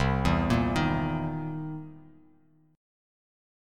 C7#9 chord